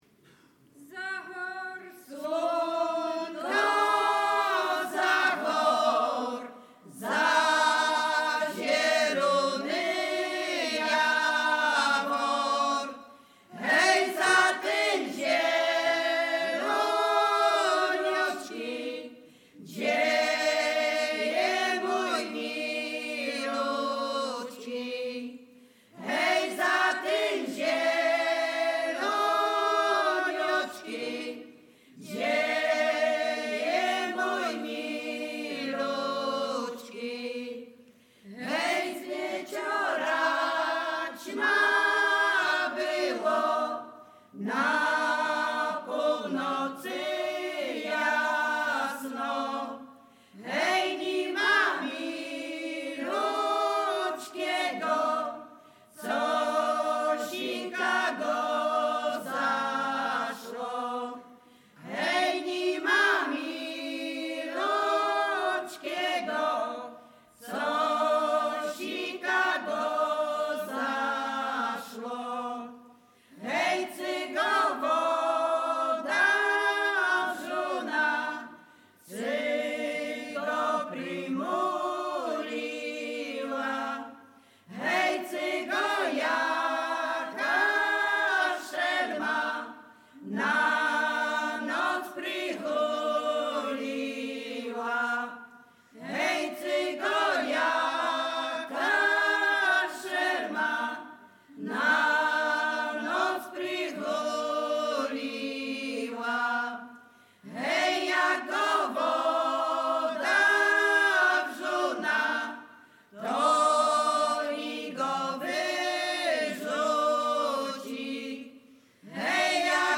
Bukowina Rumuńska
liryczne miłosne